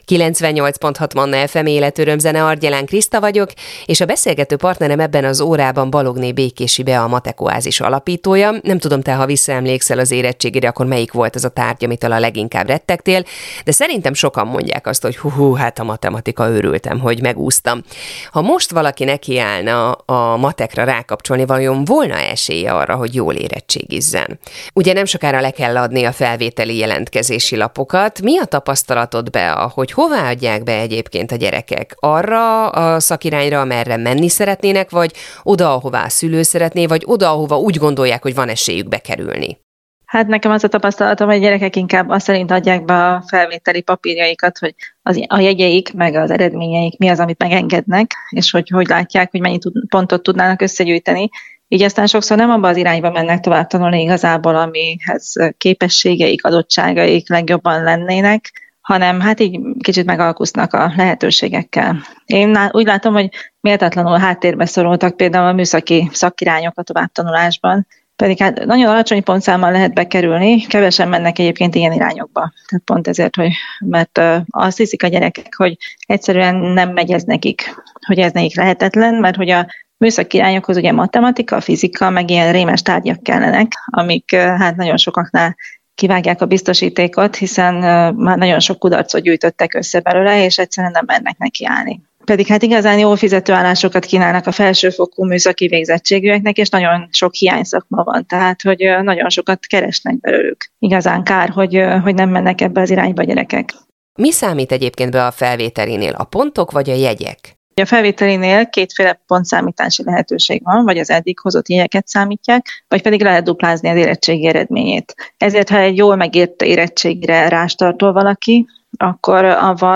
Rádió riport: Ha valaki most rákapcsolna a matekra, volna-e esélye, hogy jól érettségizzen?
Ez az írás a Manna FM rádióban 2022. február 12-én elhangzott riport alapján készült.